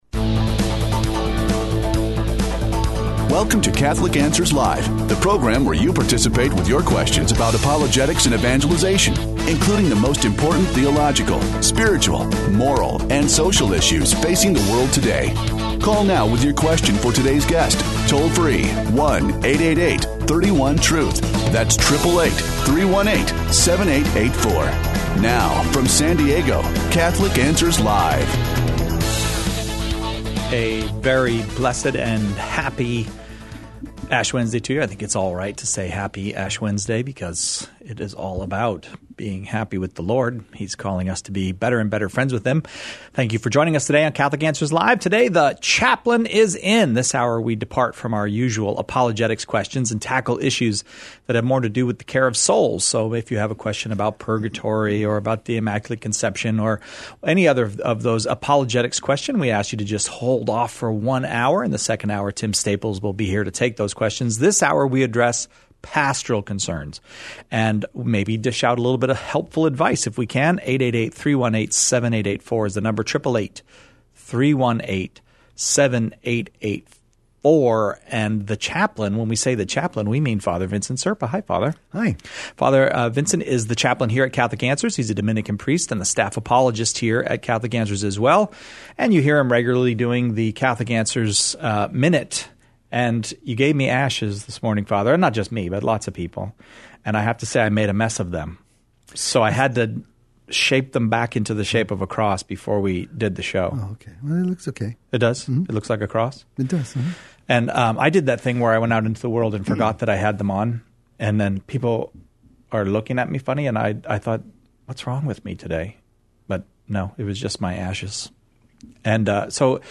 takes questions of a pastoral nature in this hour devoted to the care of souls, growth in the spiritual life, and healthy relationships.